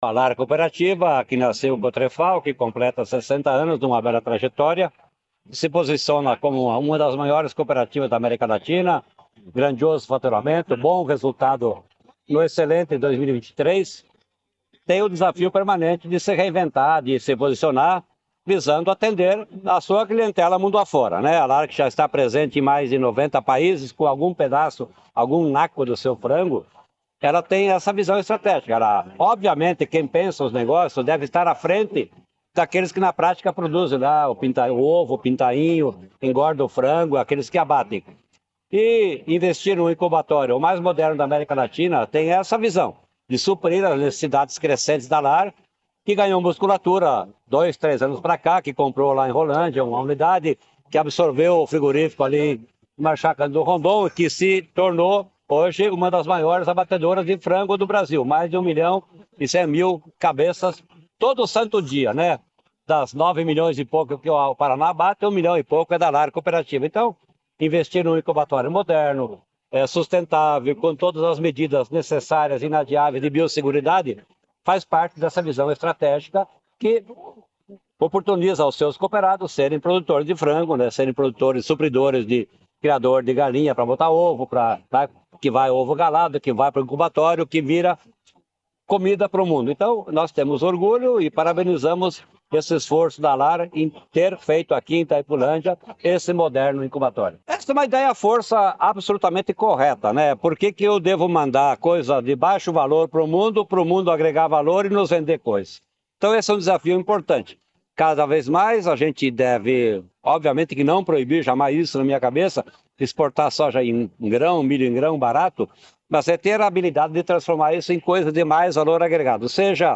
Sonora do secretário Estadual da Agricultura e Abastecimento, Norberto Ortigara, sobre a inauguração da nova incubadora de ovos da Lar, em Itaipulândia